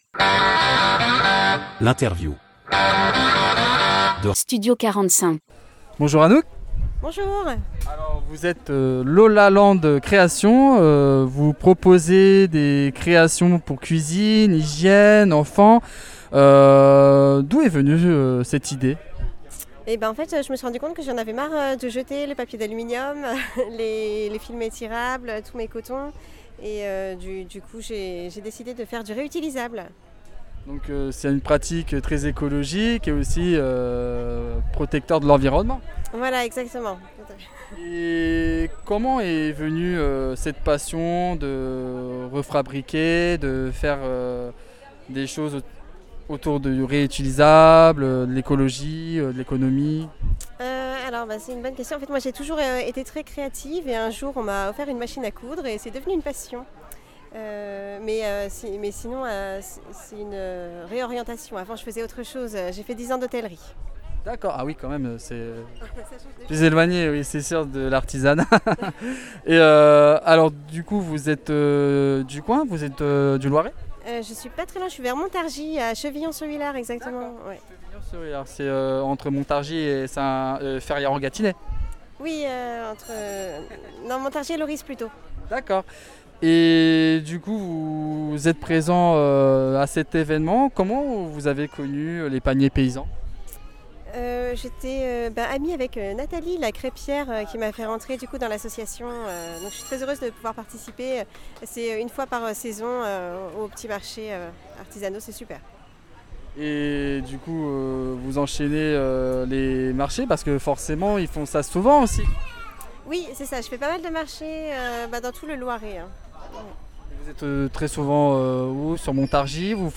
Interview de Studio 45 - spécial fête de l'été